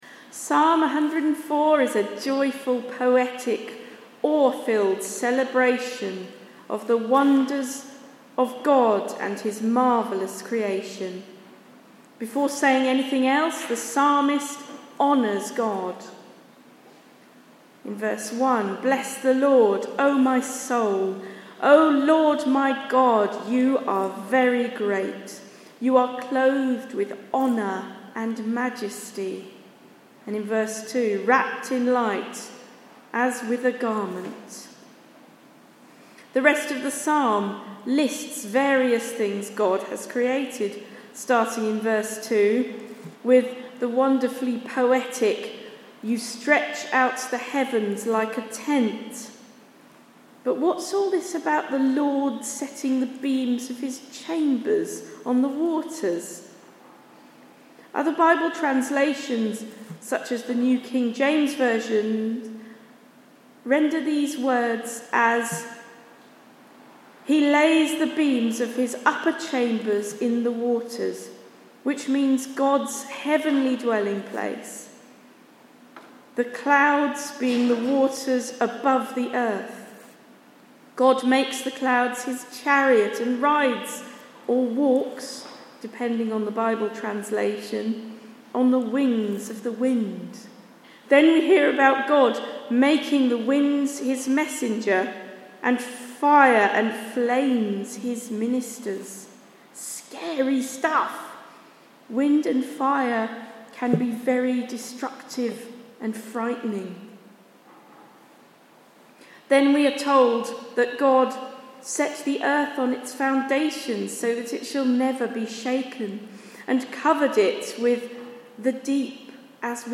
Serving one another and creation, sermon preached on 20 October 2024